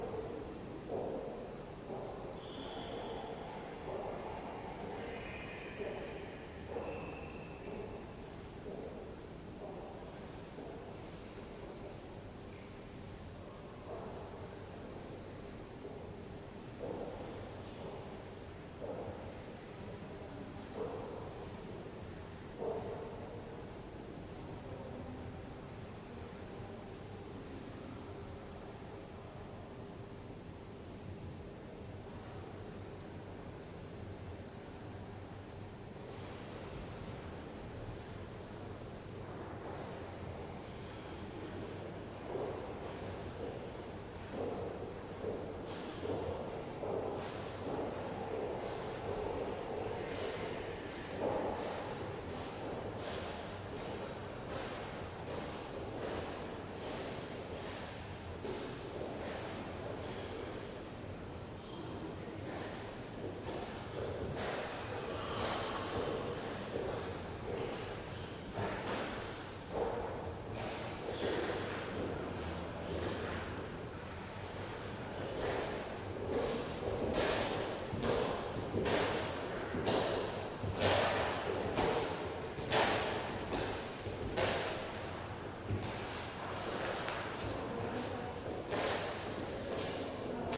车库 " 楼梯间
描述：录音是在德国莱比锡市中心的一个停车场的楼梯间进行的。
Tag: 环境 城市 回声 电梯 现场录音 车库 大厅 室内 大房间 大满贯